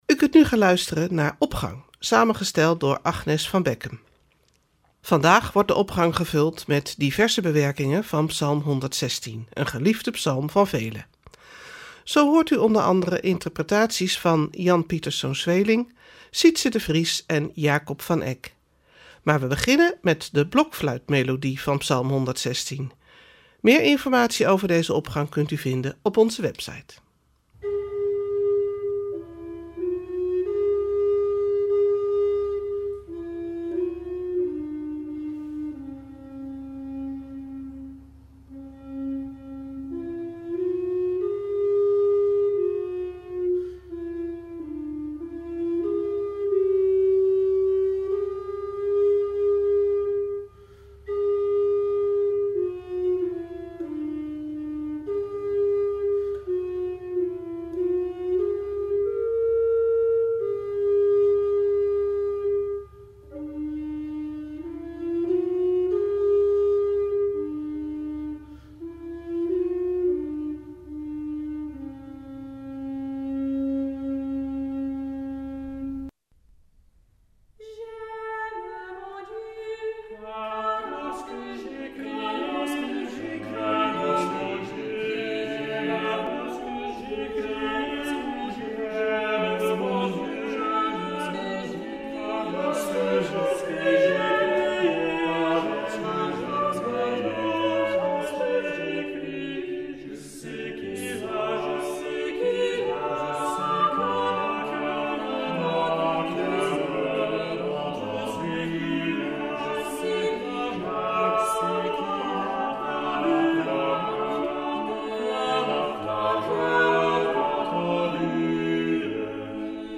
Opening van deze zondag met muziek, rechtstreeks vanuit onze studio.
Koorbewerking
orgelimprovisatie
blokfluitvariatie